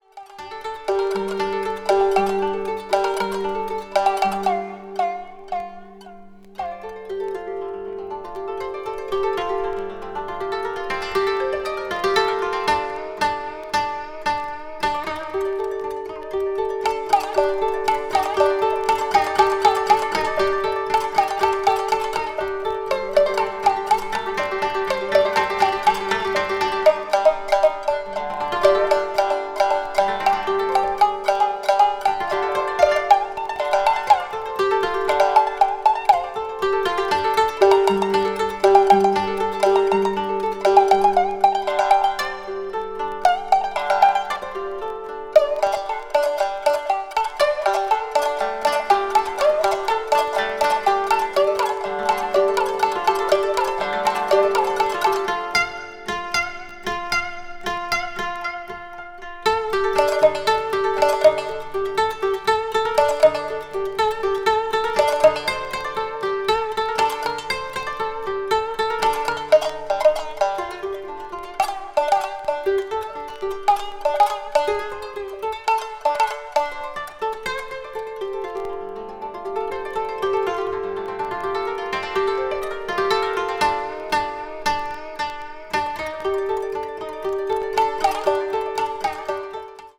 media : EX/EX(some slightly noises.)
solo shakuhachi performance
traditional Japanese orchestra